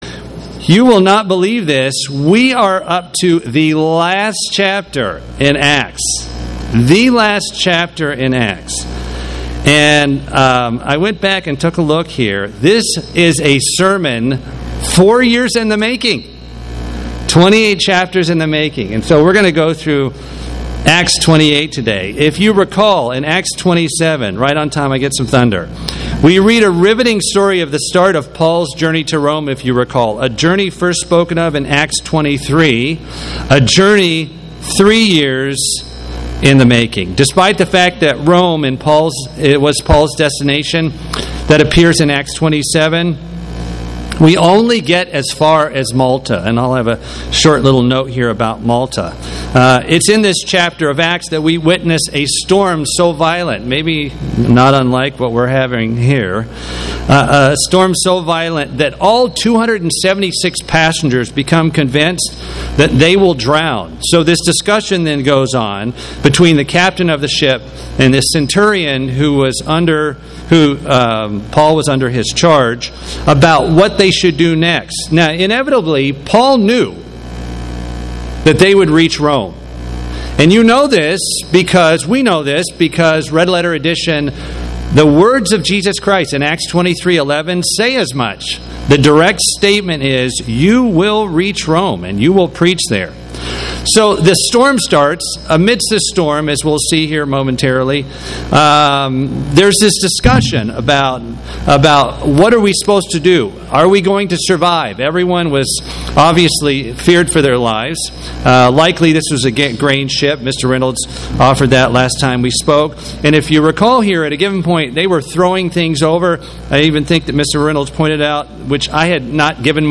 Given in Atlanta, GA
Acts protection listen Rome Prison sermon book of Acts Studying the bible?